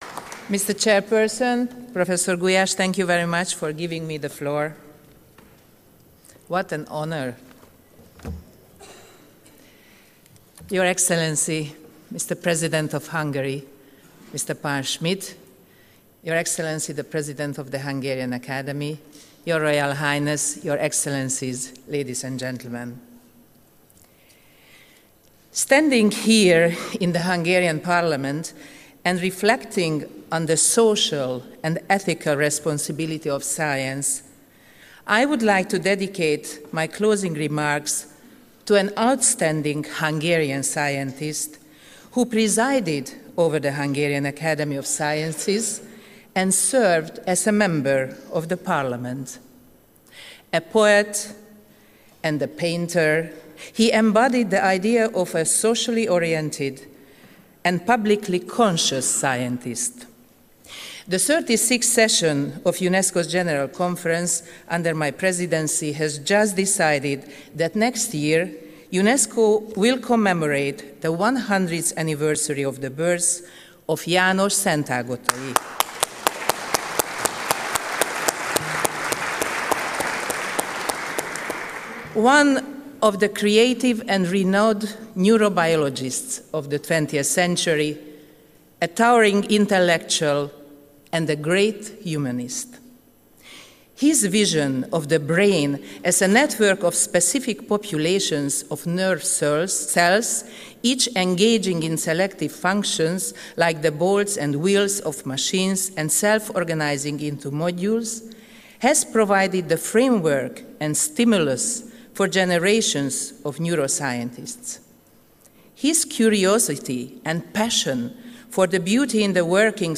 Lecture by Katalin Bogyay | VIDEOTORIUM
World Science Forum 2011 , Closing Plenary Lectures